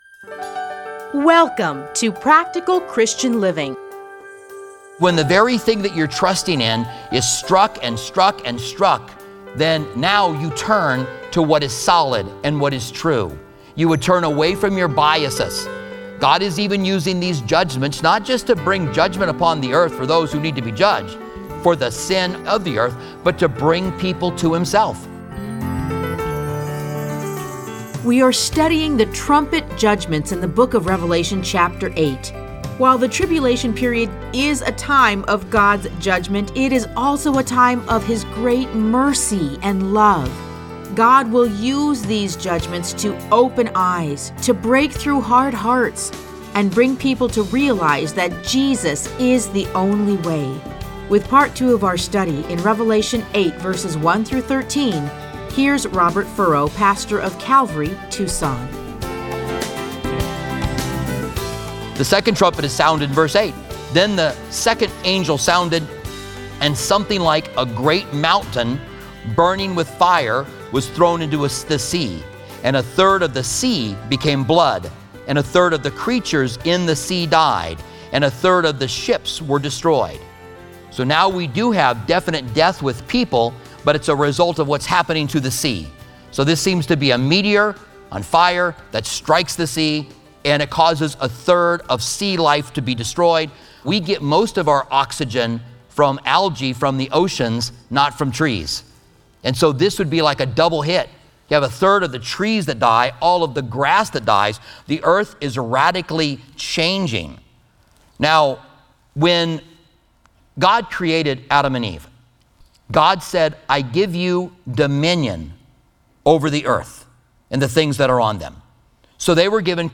Listen to a teaching from Revelation 8:1-13.